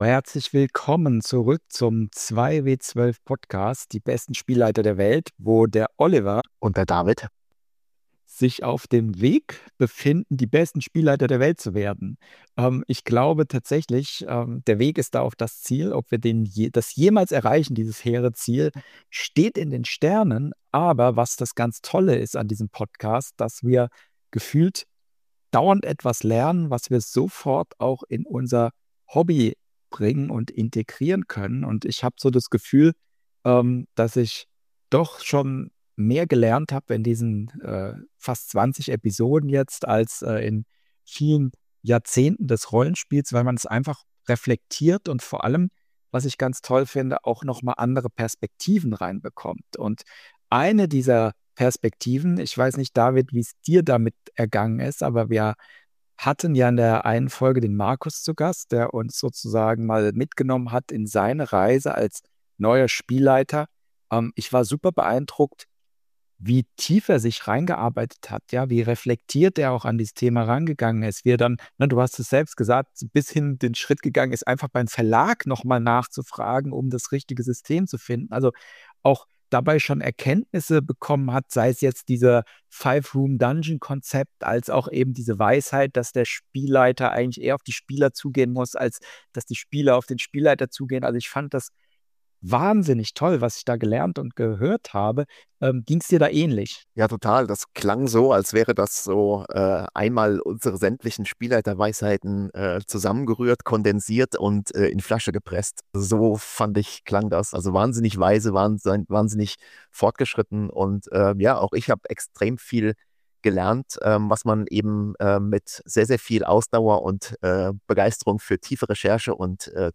Alt in der Spielleitung? Unser Erfahrungsschatz im Interview. (Ep. 19) ~ 2W12 - Die besten Spielleiter der Welt Podcast